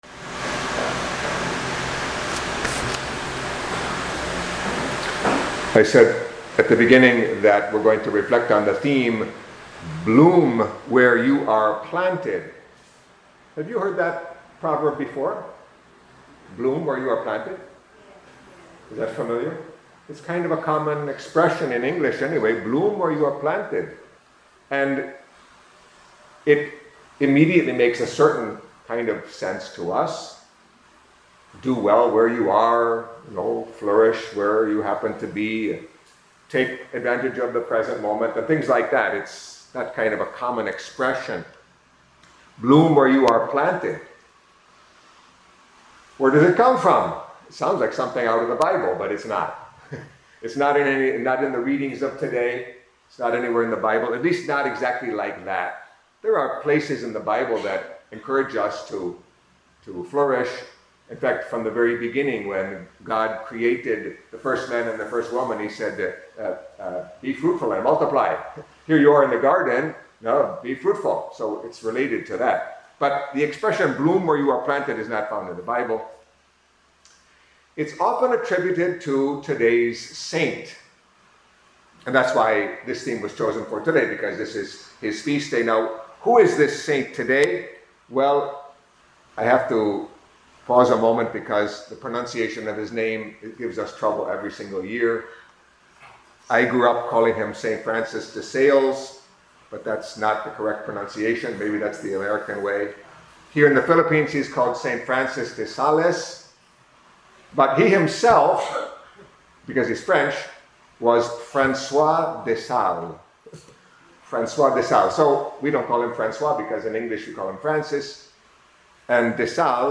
Catholic Mass homily for Saturday of the Second Week in Ordinary Time